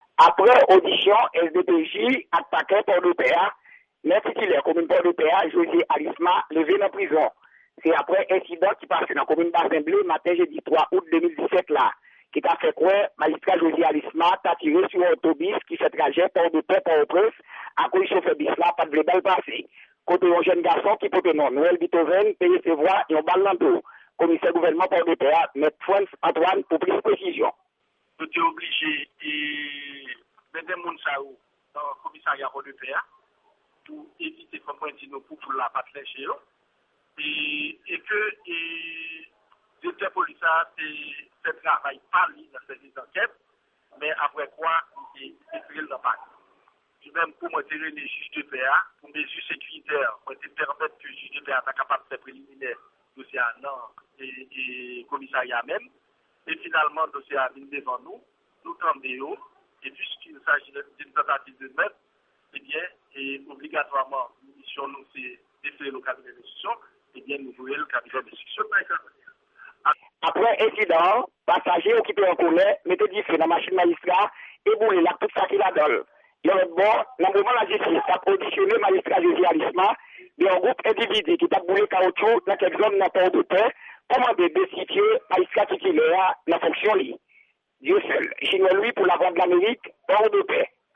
Yon repòtaj korespondan Lavwadlamerik